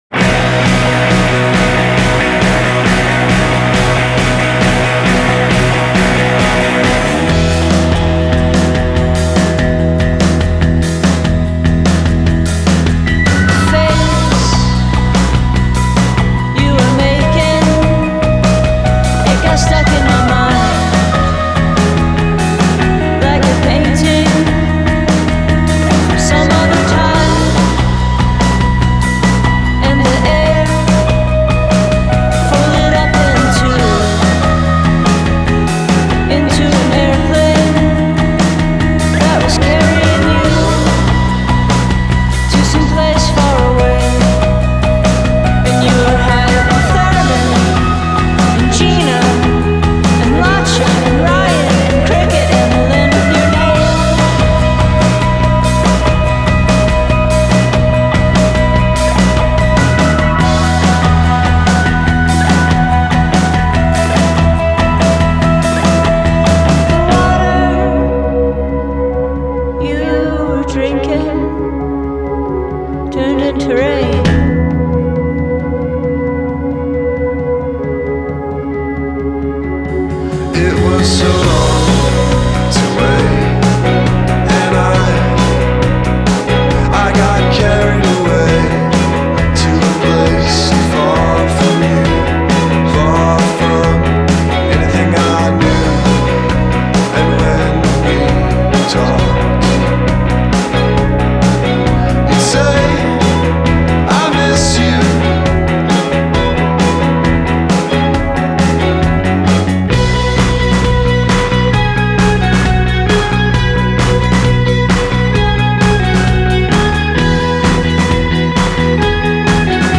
Great guitar work on this record.